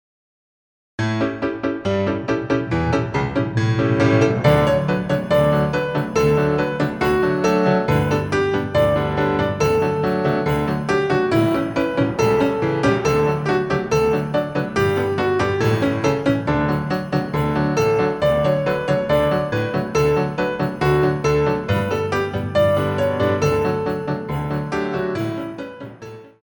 Battement Frappé